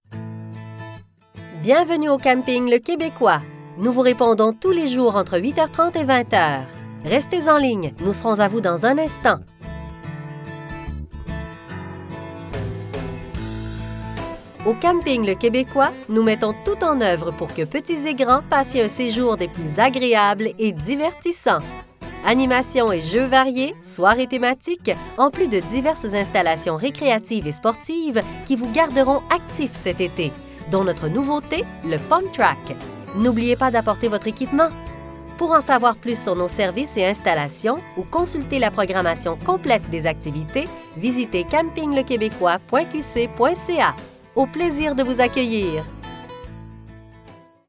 attente téléphonique